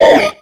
Cri de Makuhita dans Pokémon X et Y.